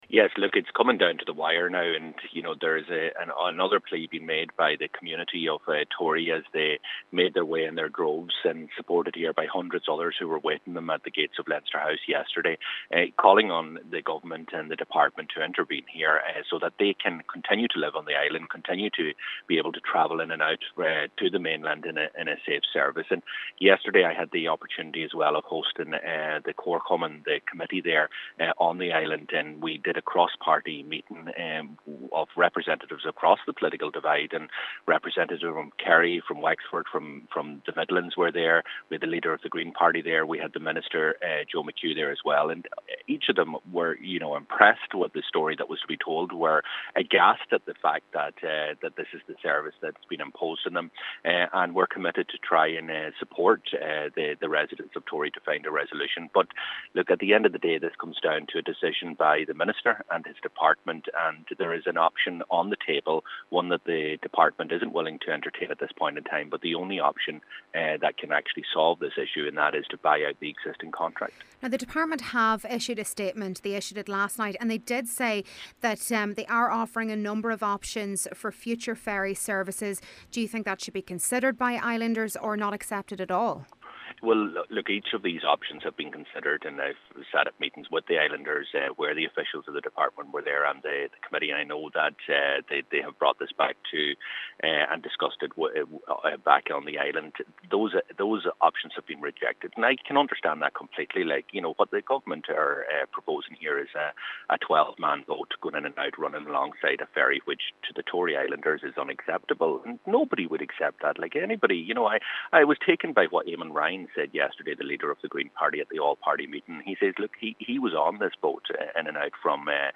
Donegal Deputy Pearse Doherty says there’s growing anger over plans to service the island with a vessel which is over forty years old.